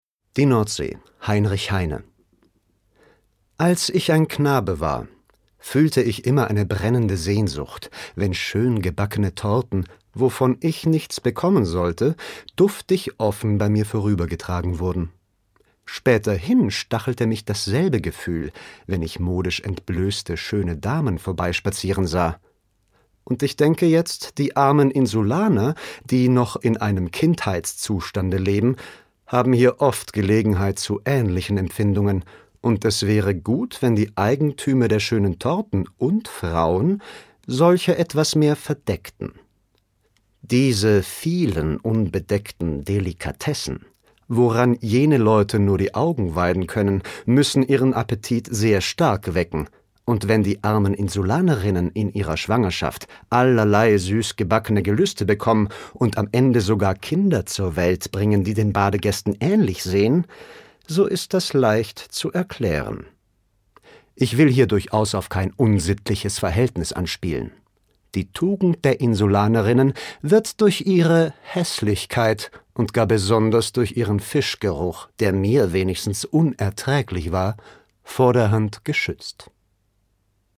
virtuose Gitarrenkünstler